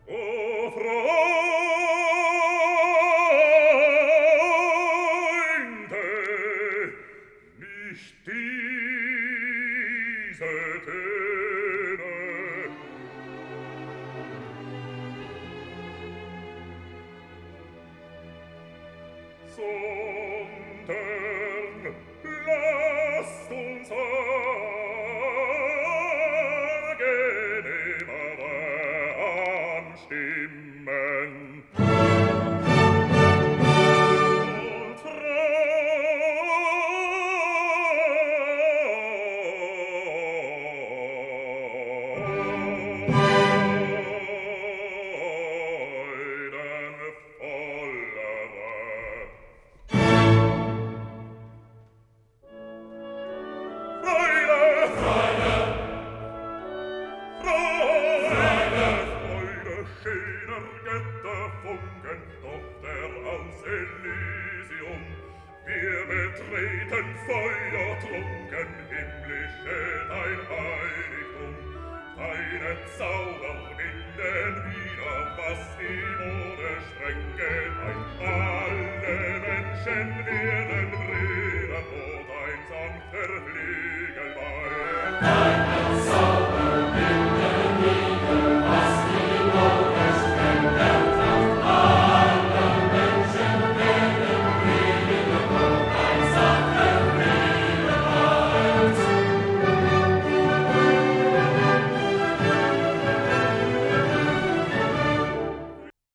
Beethoven first replays for us the themes that we just heard of the first three movements.
This is dramatically proclaimed by the baritone soloist.
This is followed by the choir trying it on for size as well.
By the way, the snippets of audio we’ve been listening to are from that 1972 recording of the Chicago Symphony Orchestra and Chorus, Georg Solti conducting, recorded at the Krannert Center of Performing Arts on the campus of the University of Illinois.